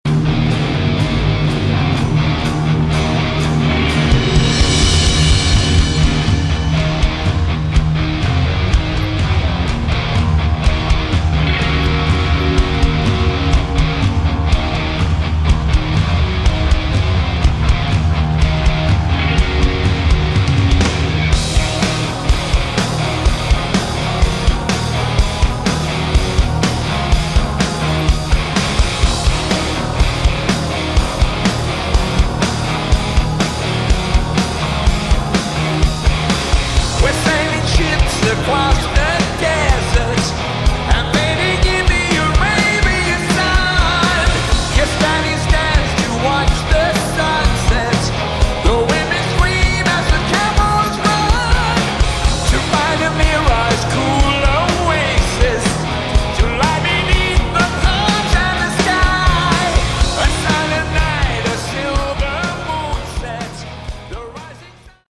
Category: Hard Rock
vocals
guitar
bass
keyboards
drums